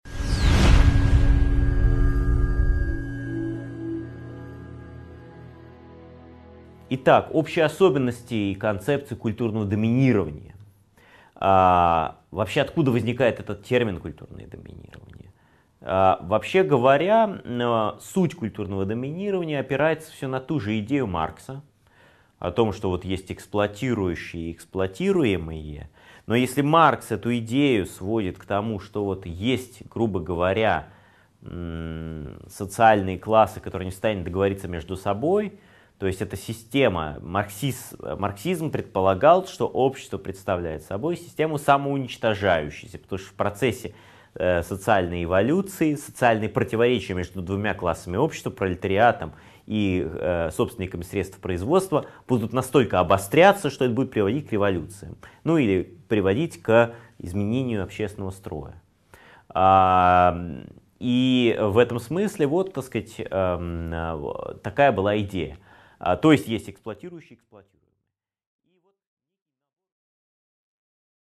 Аудиокнига 12.3 Особенности концепции культурного доминирования | Библиотека аудиокниг